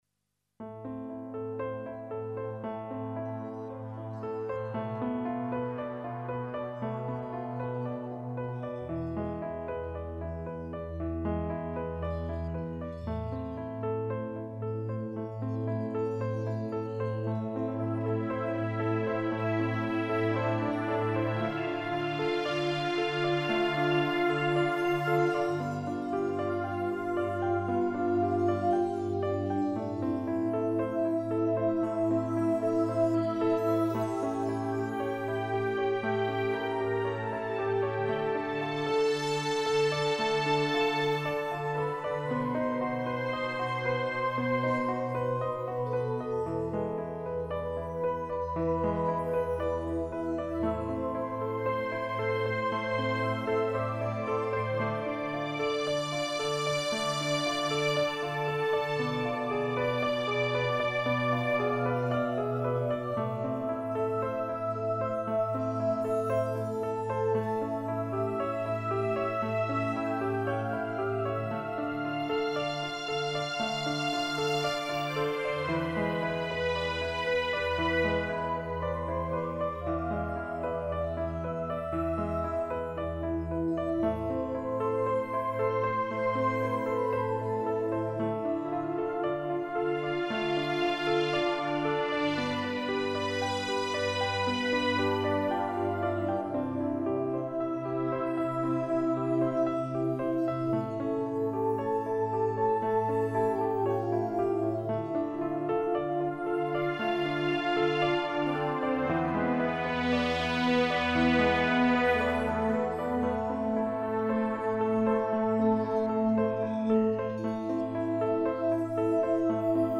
Composizioni che evidenziano temi cantabili e armoniosi.